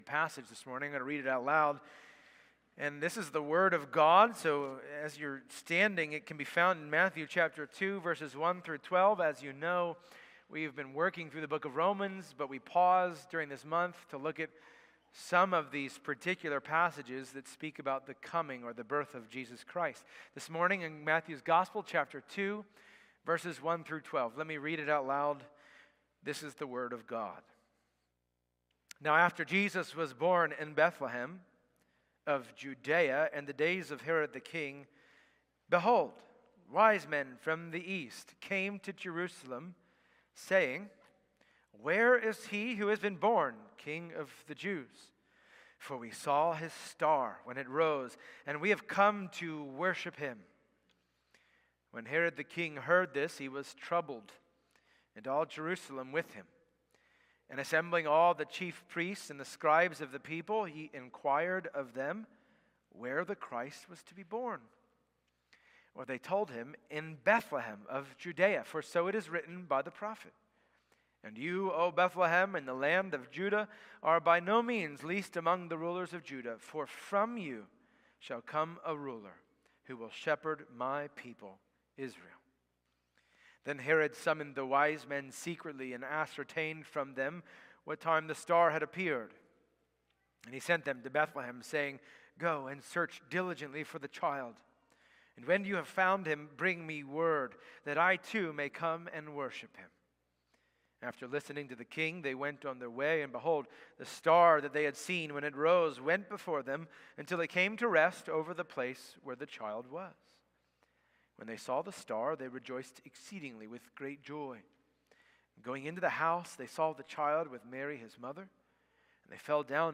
December-22-Worship-Service.mp3